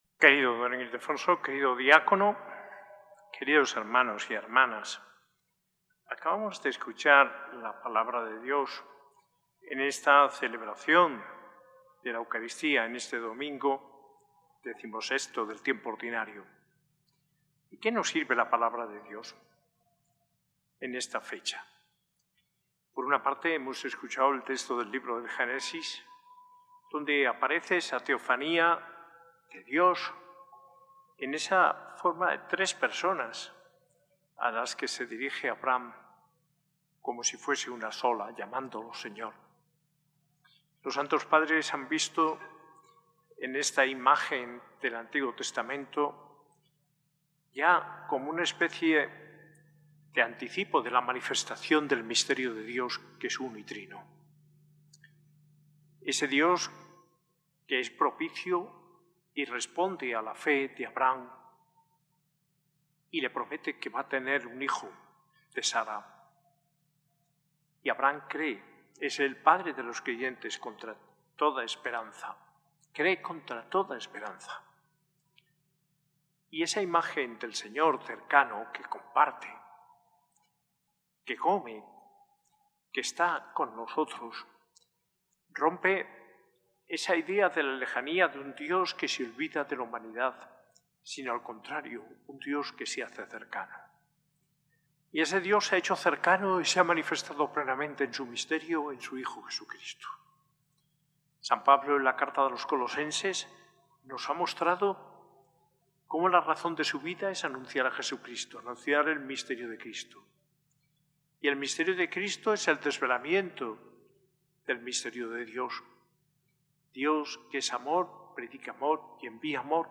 Homilía en la Eucaristía del domingo XVI del Tiempo Ordinario y jubileo de los migrantes, por el arzobispo Mons. José María Gil Tamayo, el 20 de julio de 2025.